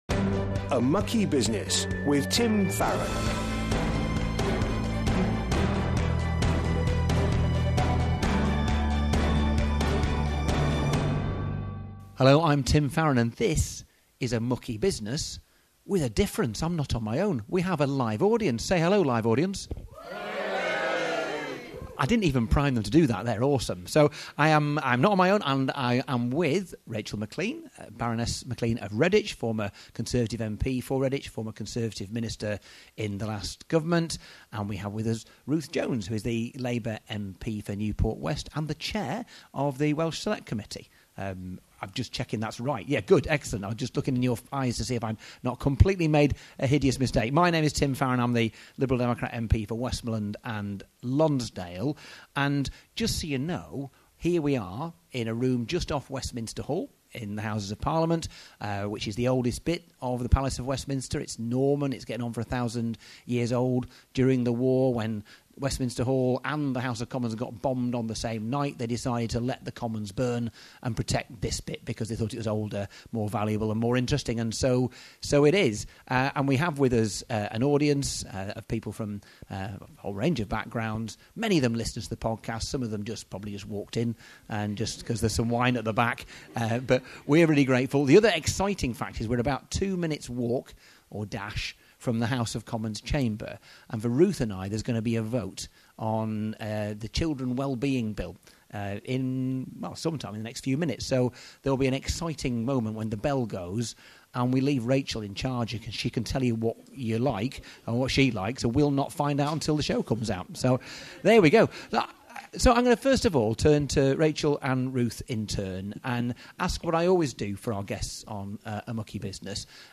Last night, Labour MP Ruth Jones and Conservative Peer Rachel McLean joined Tim for a fascinating conversation about the role of Christian politicians in today’s increasingly polarised world. With a live audience present, they had the chance to ask their questions in person.